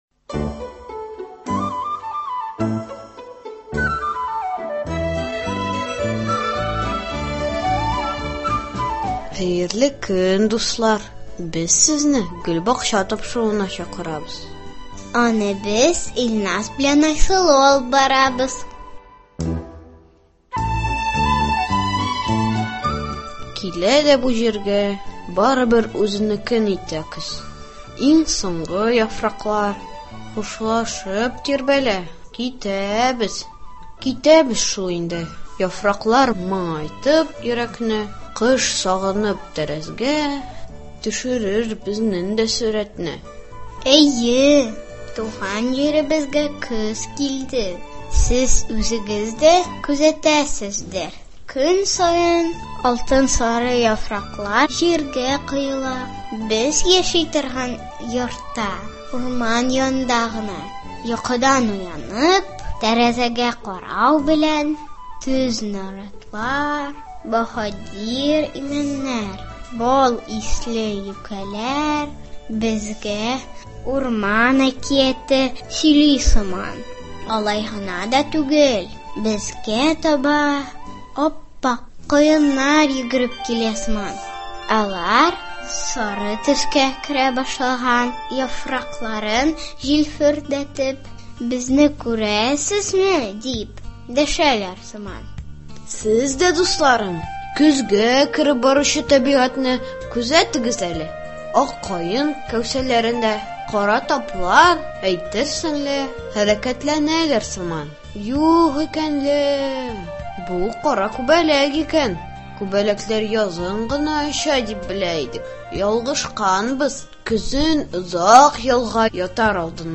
Матур җырлар җырлый-җырлый аларга без дә иярик әле.